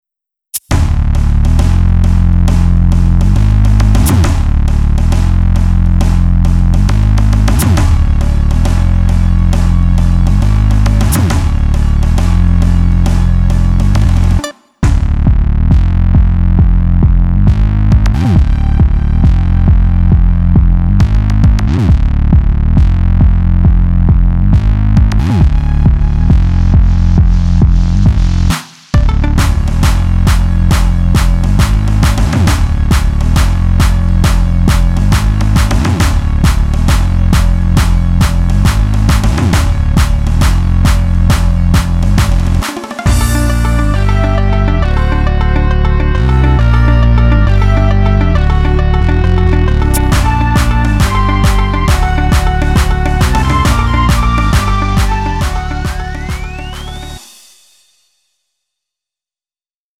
음정 -1키 3:05
장르 가요 구분 Lite MR
Lite MR은 저렴한 가격에 간단한 연습이나 취미용으로 활용할 수 있는 가벼운 반주입니다.